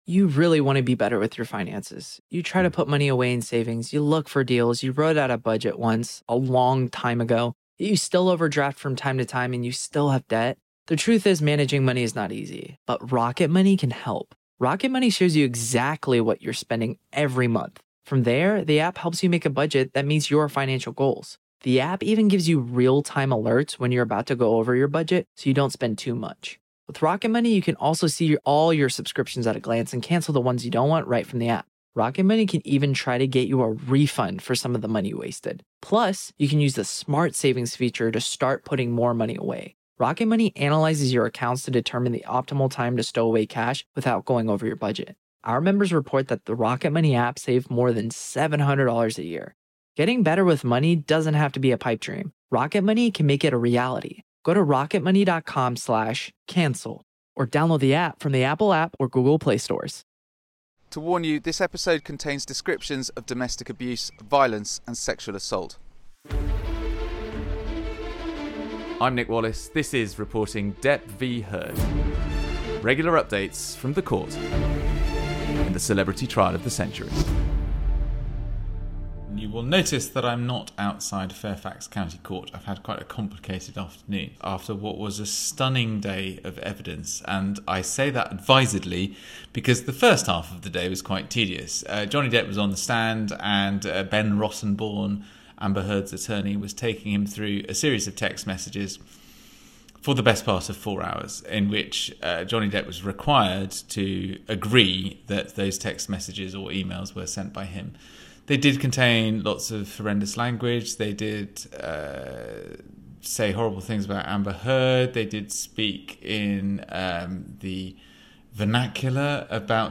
Plus there’s a podcast-exclusive interview